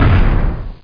impact1.mp3